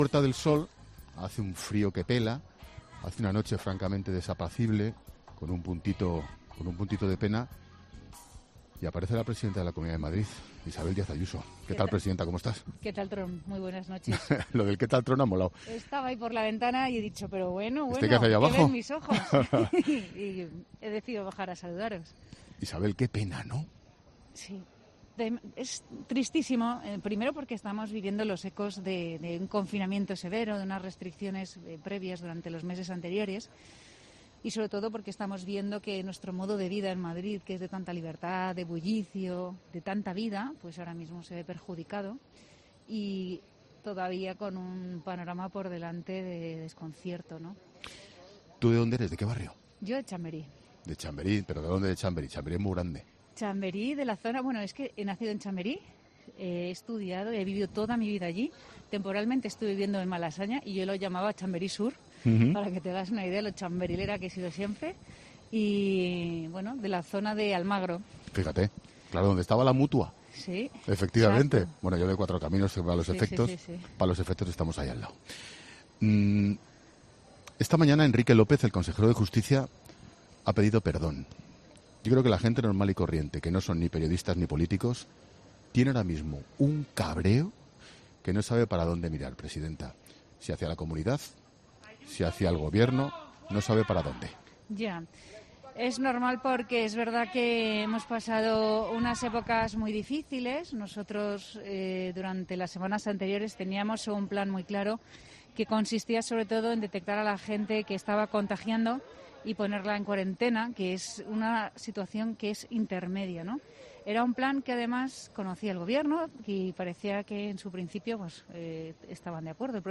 Escuchamos a la presidenta madrileña Isabel Díaz Ayuso anoche con Angel Expósito: